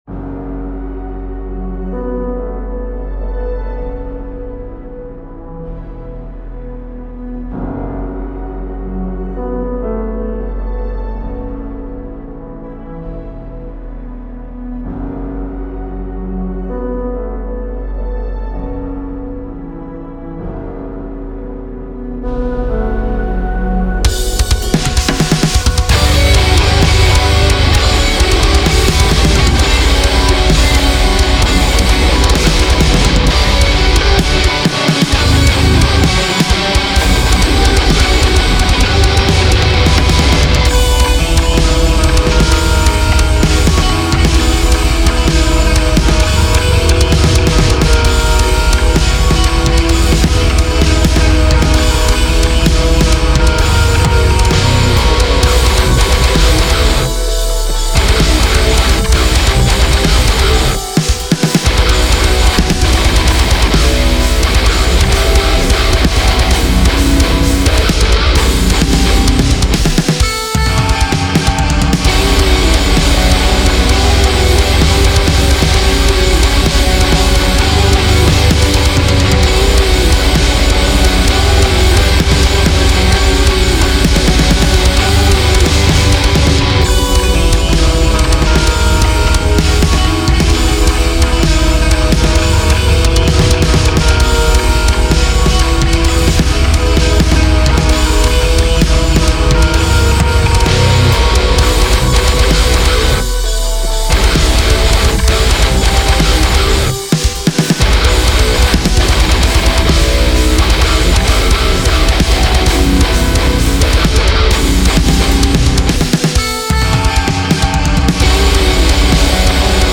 Оцените сведение рок\метал песня
Сочинением тяжелой музыки в принципе занимаюсь долго, но именно песен с чистым вокалом написал всего две, это третья.
V6 это шестая версия, а сейчас V13, но тут в шестой версии записано на динамический микрофон от караоке. 11111 - Ocean, это первое мое сведение, это было несколько лет назад и восстановить я не могу уже, хотя там звук приличный получился и все говорили что хорошо сделано.